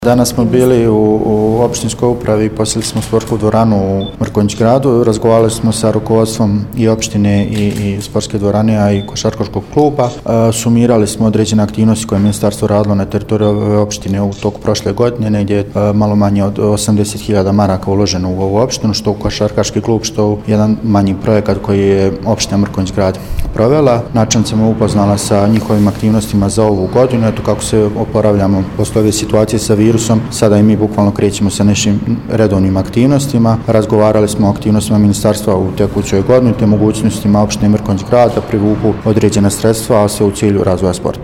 izjavu